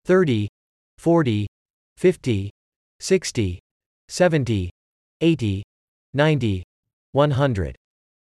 30-100 prononciation en anglais: